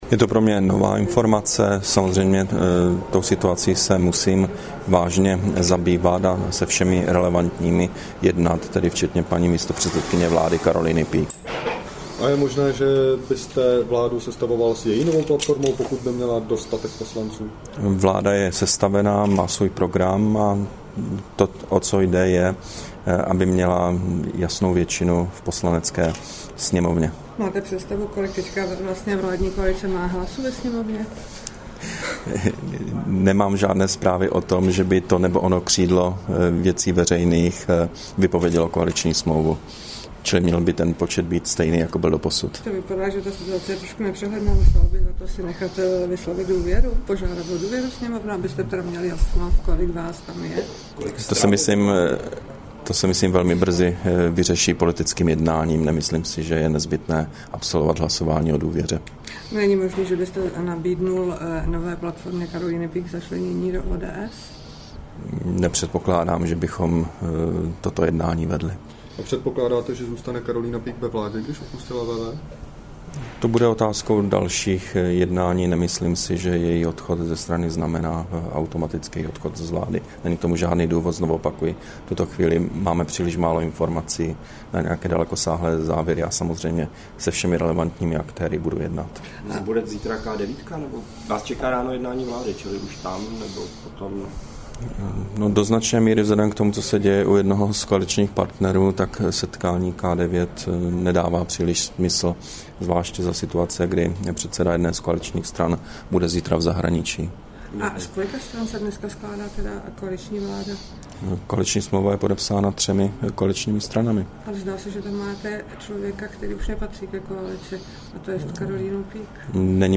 Předseda vlády Petr Nečas se vyjádřil k aktuální situaci po příletu ze zahraniční cesty.
Brífink premiéra k aktuální politické situaci, 17. dubna 2012